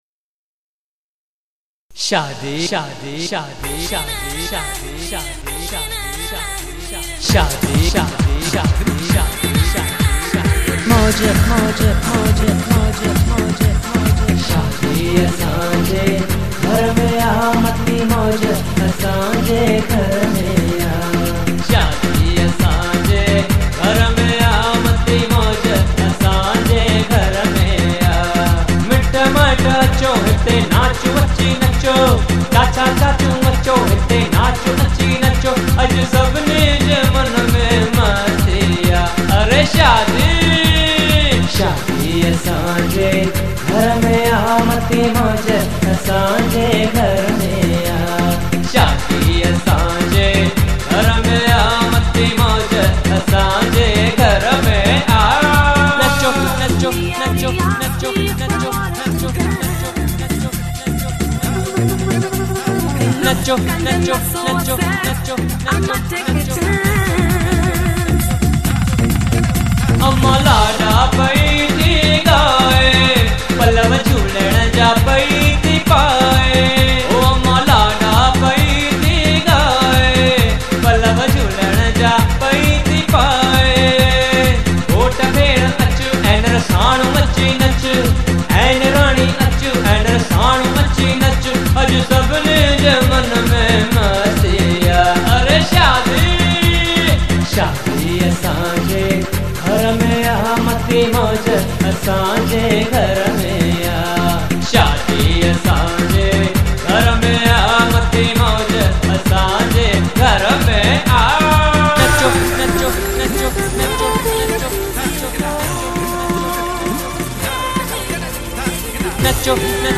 Sindhi Songs for Lada (Ladies Sangeet)